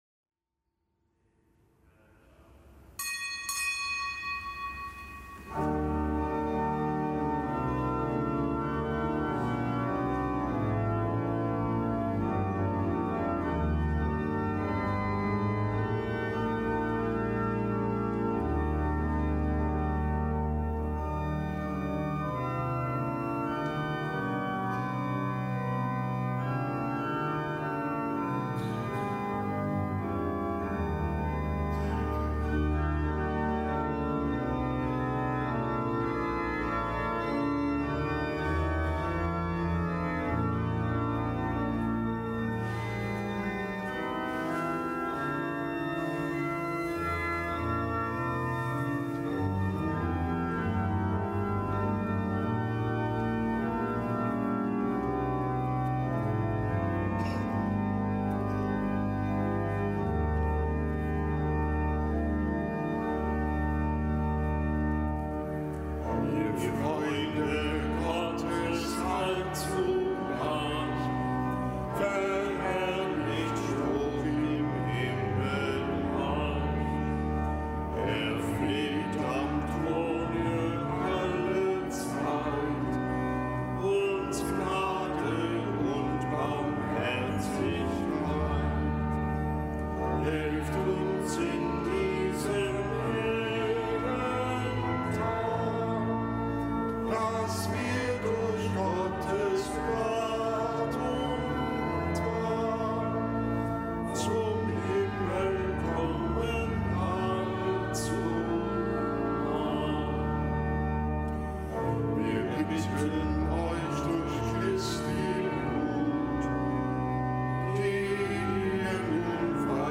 Kapitelsmesse am Gedenktag des Heiligen Franz von Sales